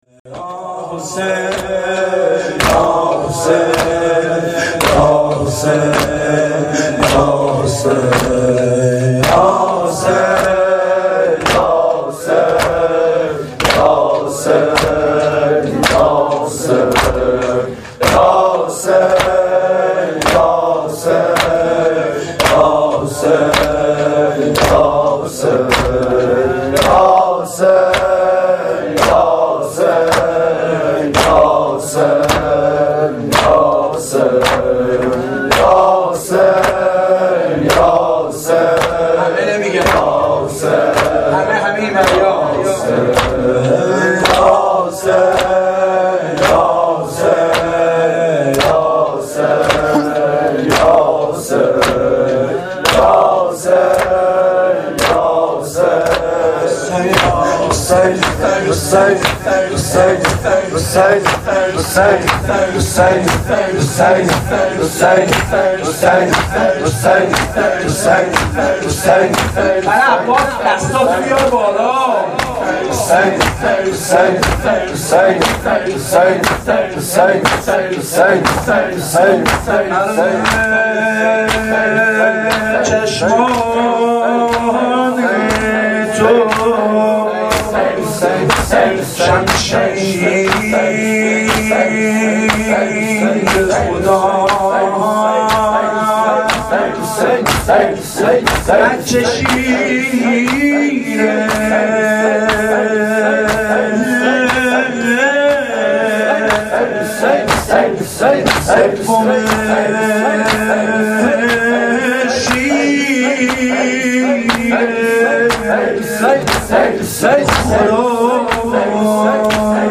نوای فاطمیه, مداحی فاطمیه